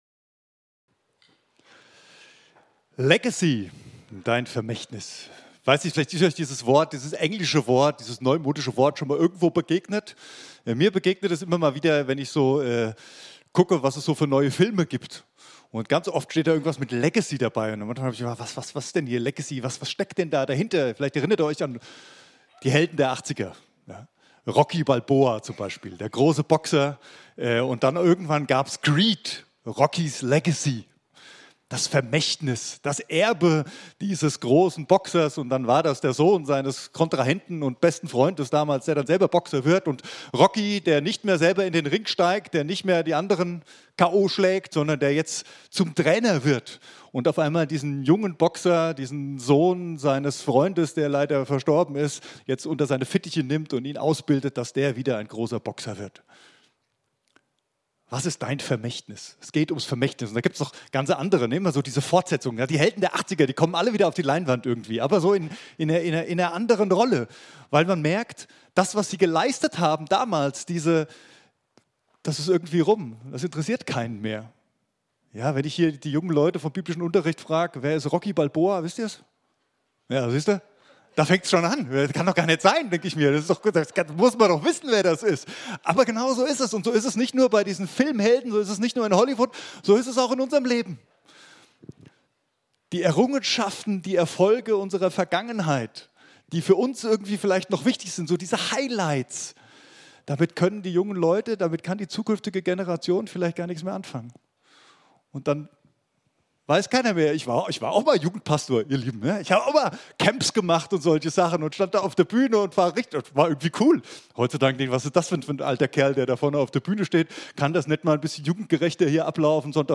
predigt_251005.mp3